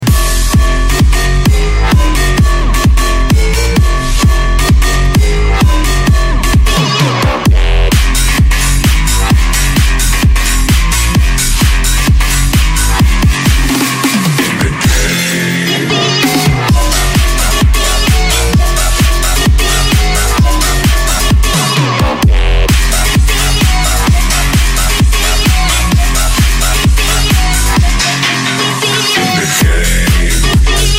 Скорее даже не пресет нужен, а вообще как называется такого рода Басс, может по названию найду какие нибудь туториалы Если конечно и пресет есть то буду благодарен за него Речь идет об этом "Железном" саунде